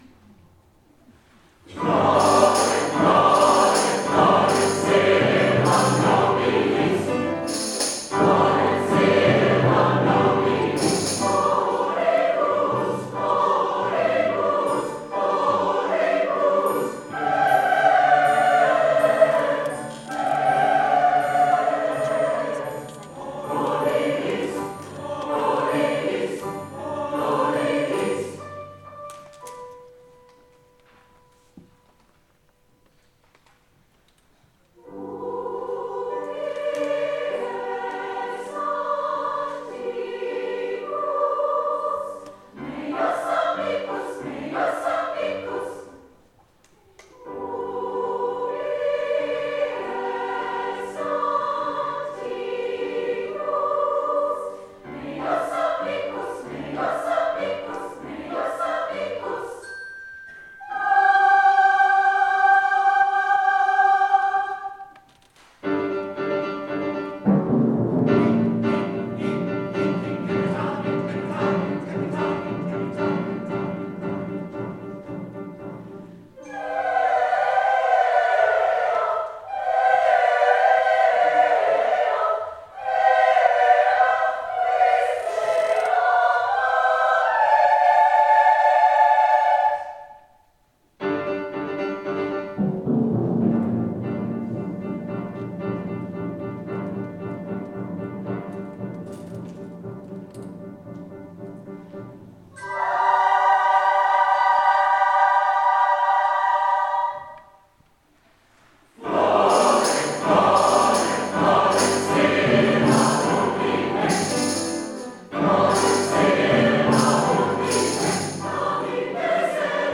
our May 4th concert
Floret silva nobilis    Coro